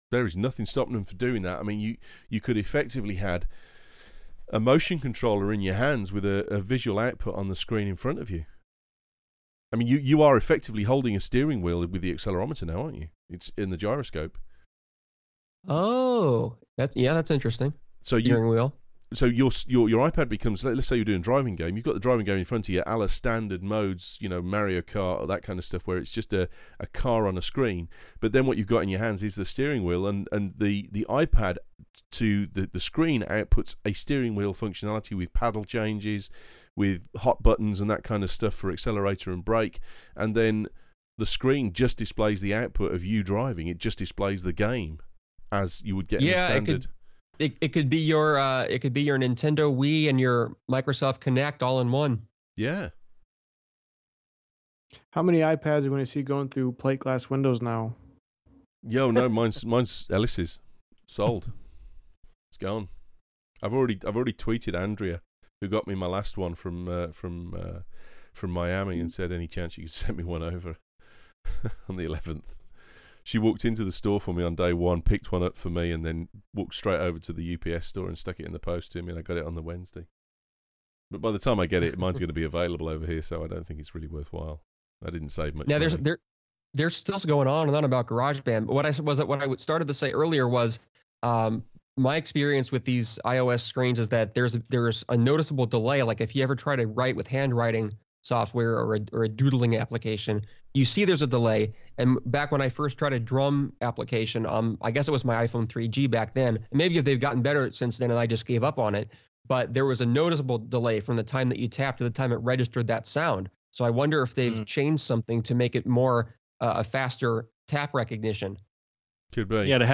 MORE uncut Audio from the Launch of the iPad last night - Sorry that its in two parts, but I couldnt get a single one small enough